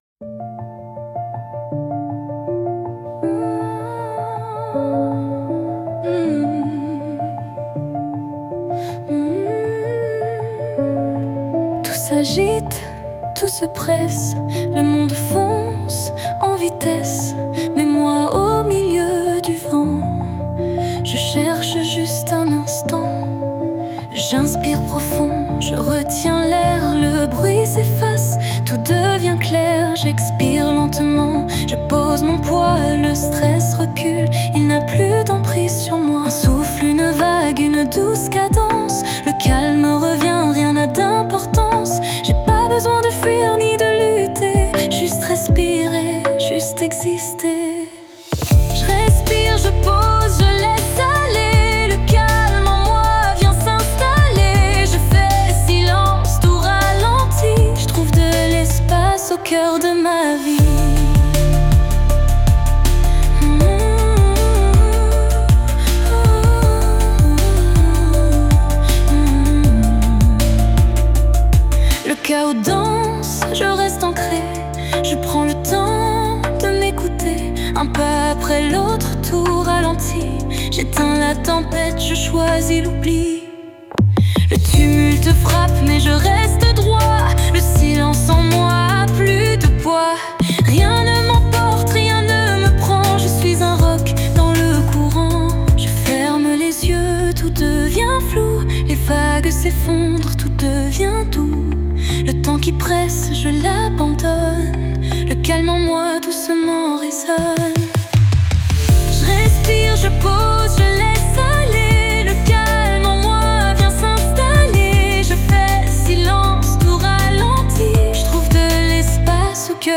Préparez-vous à découvrir des outils simples et efficaces pour naviguer avec légèreté dans ce monde agité, à commencer par cette douce mélodie que je vous propose d’écouter confortablement installée :